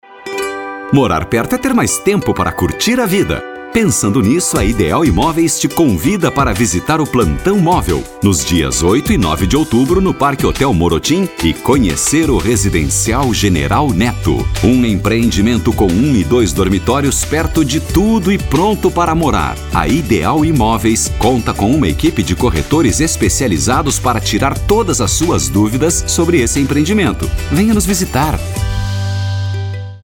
Locutor voz Grave, a disposição para gravação de Spots de Rádio e TV, bem como Esperas Telefônicas e documentários
• spot